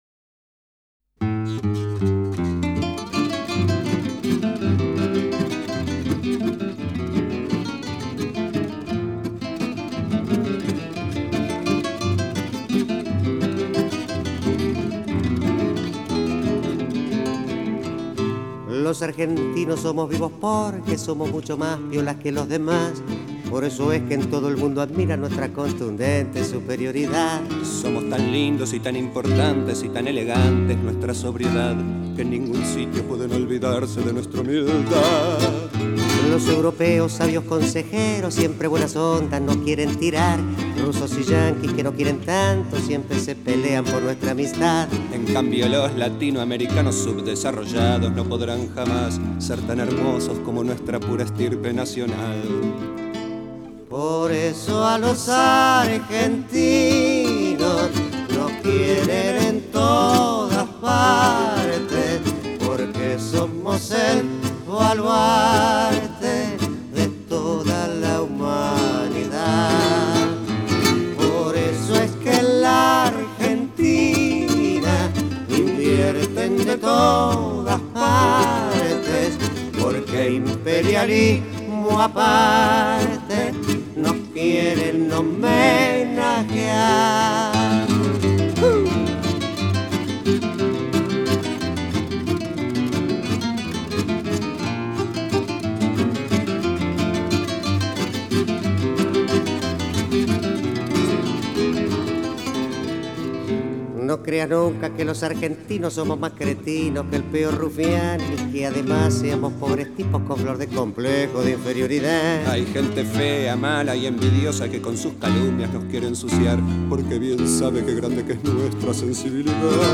Танго